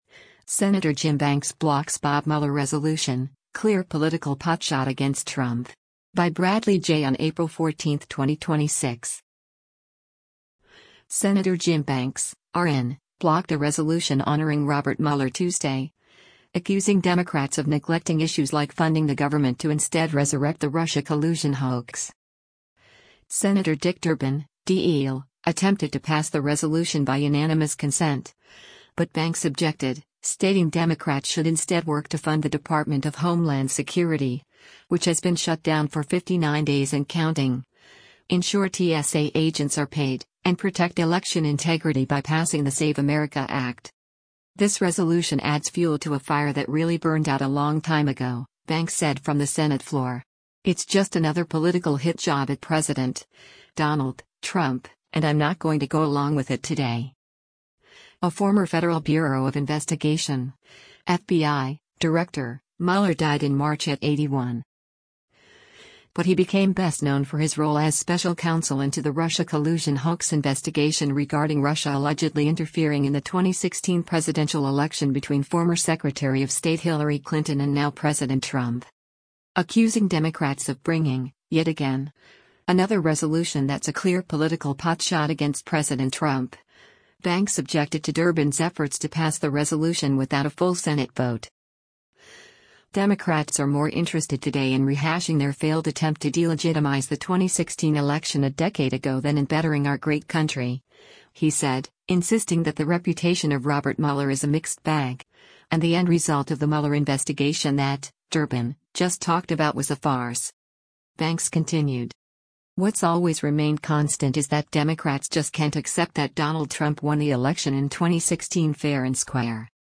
“This resolution adds fuel to a fire that really burned out a long time ago,” Banks said from the Senate floor.